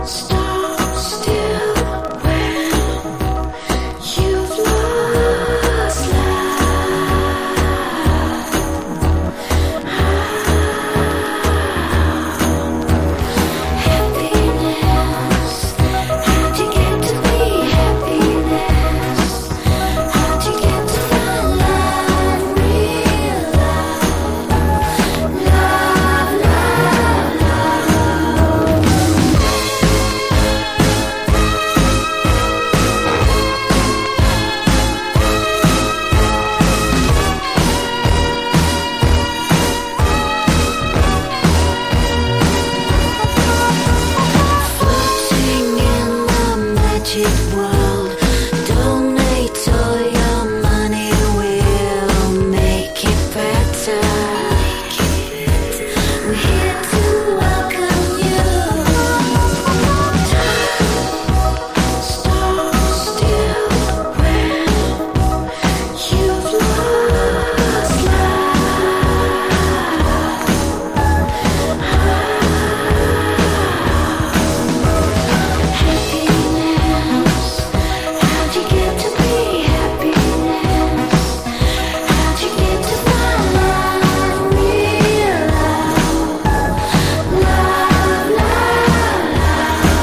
ELECTRO POP# NEO ACOUSTIC / GUITAR POP (90-20’s)